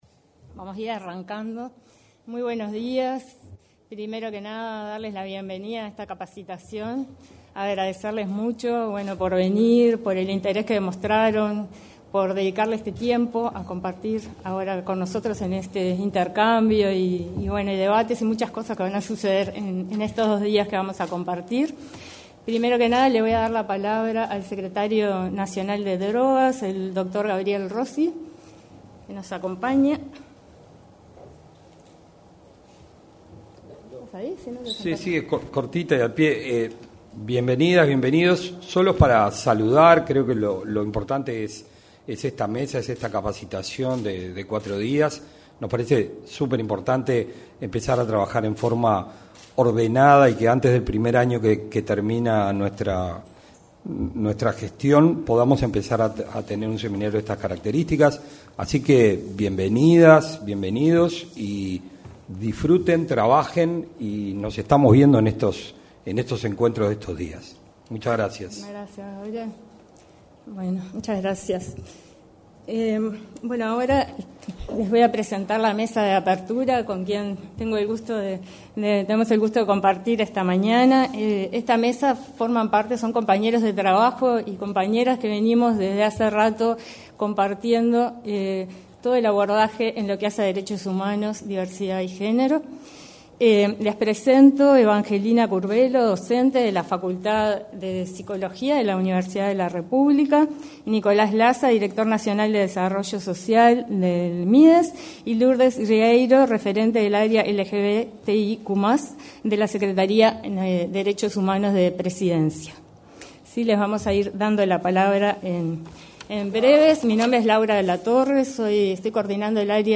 Seminario Diversidad y Consumo: Herramientas para un Acompañamiento Responsable 03/11/2025 Compartir Facebook X Copiar enlace WhatsApp LinkedIn Se realizó, en el salón de actos de la Torre Ejecutiva, el seminario Diversidad y Consumo: Herramientas para un Acompañamiento Responsable.